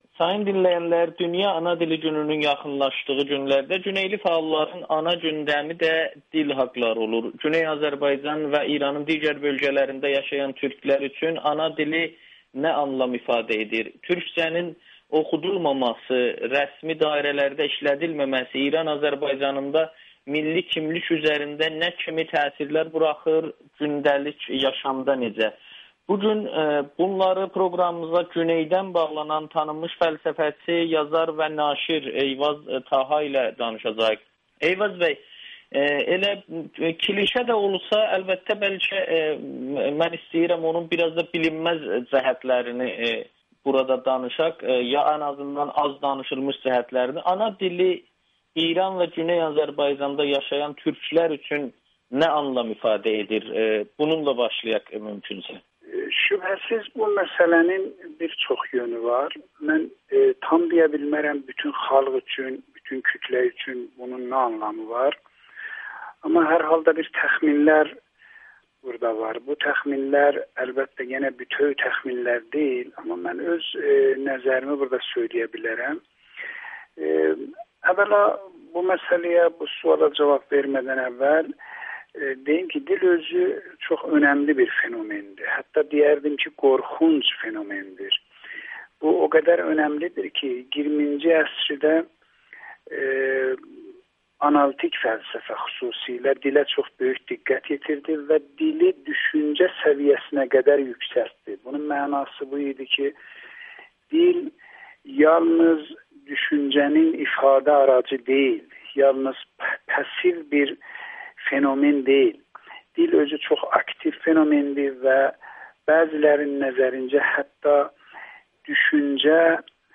Güneyli filosof Amerikanın Səsinə danışır